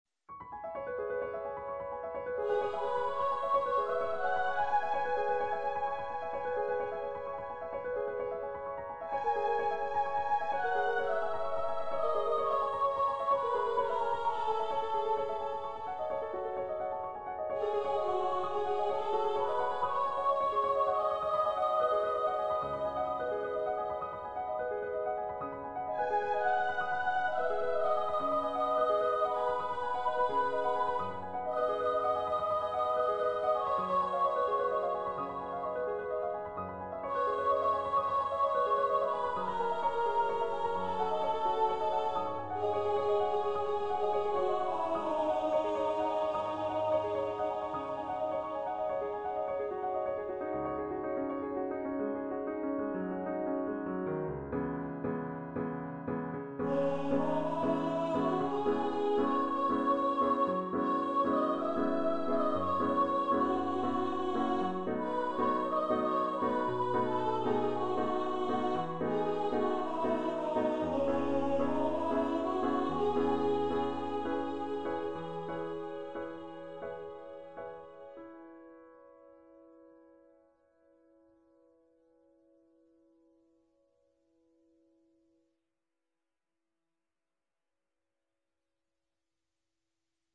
Voice and Piano
Composer's Demo